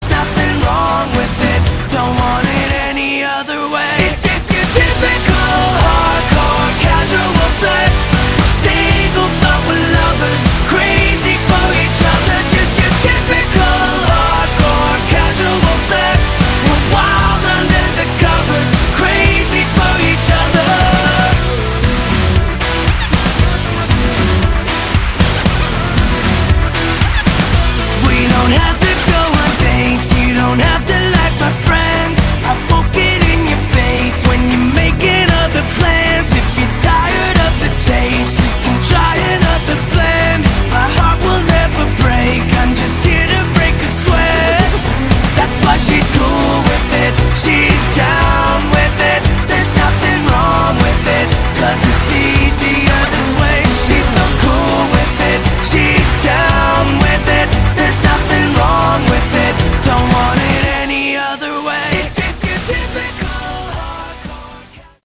Category: Modern Hard Rock
lead vocals, rhythm guitar
lead guitar, backing vocals
bass
drums, percussion
keyboard, backing vocals